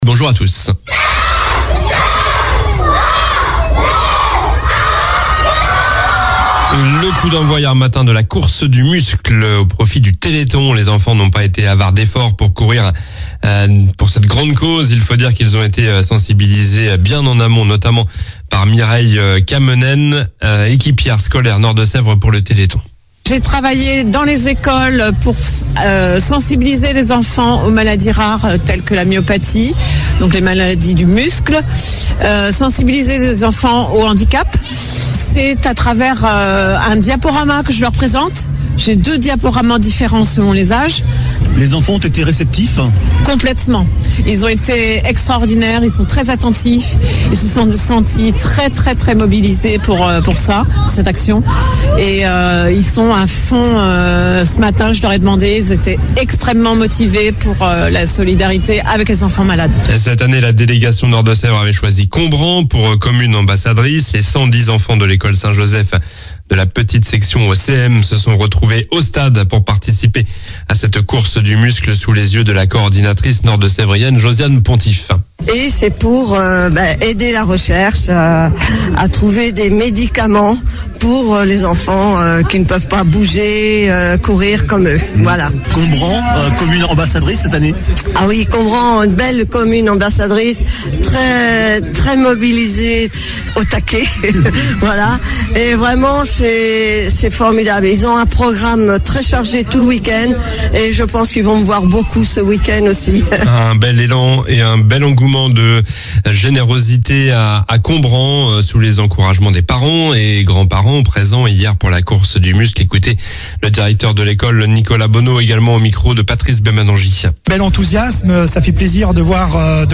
Journal du samedi 30 novembre (matin)